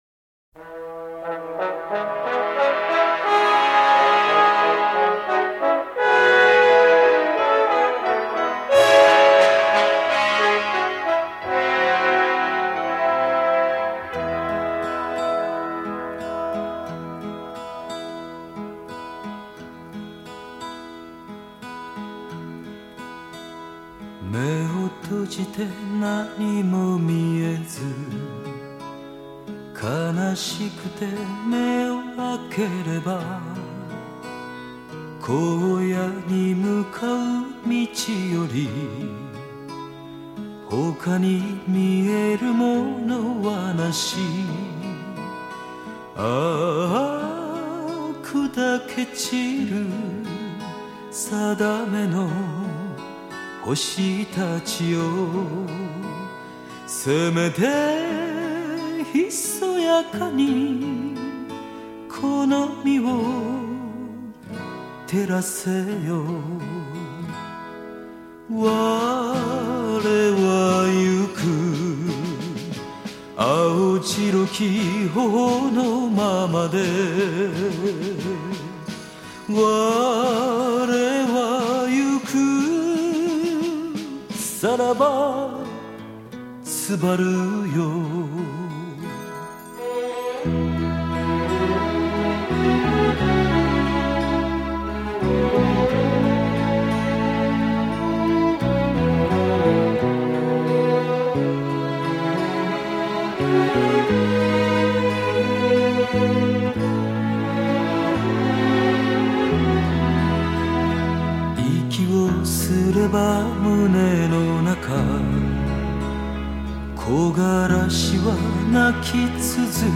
日本流行乐男歌手
无损音乐